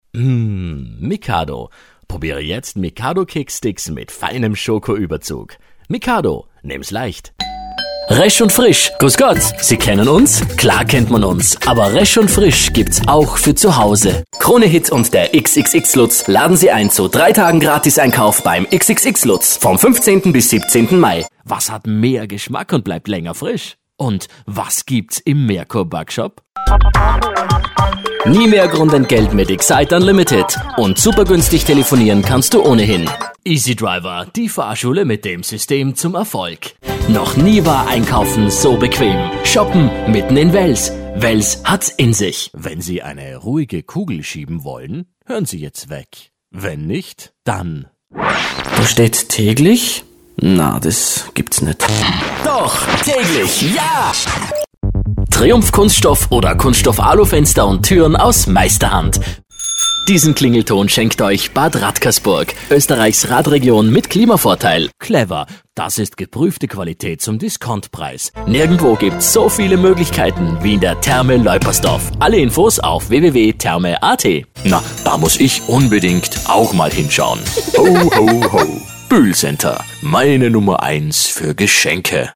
Die Top-Stimme des Monats für Werbung & Dokumentation:
Sprecher für Hörfunkspots und ORF-Fernsehmagazine
werbespotsmix2011.mp3